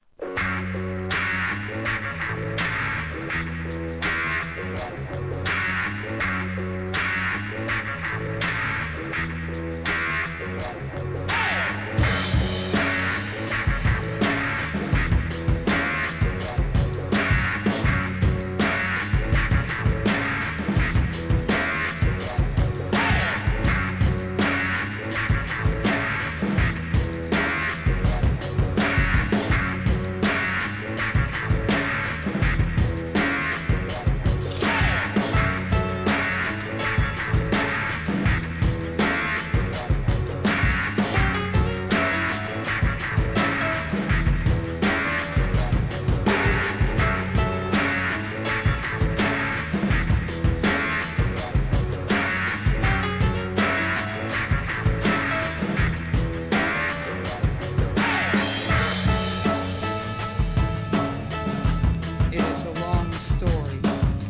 ブレイクビーツ